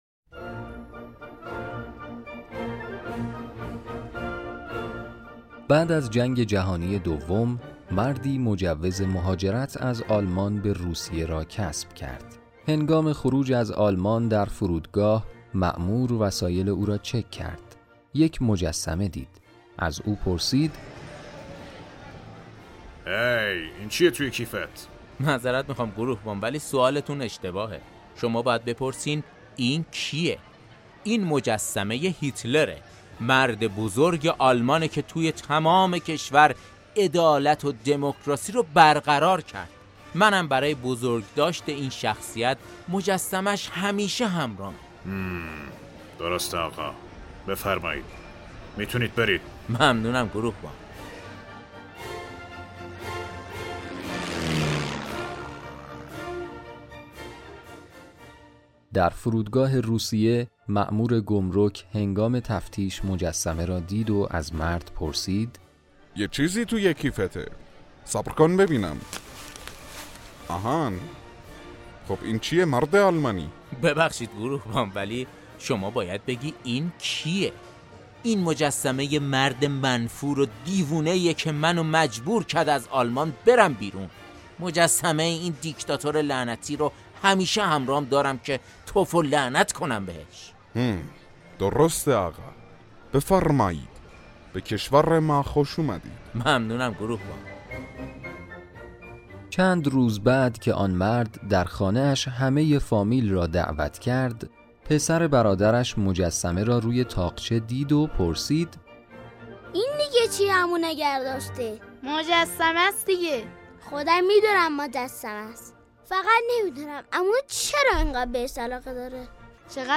جوک خنده دار صوتی